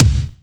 KICK144.wav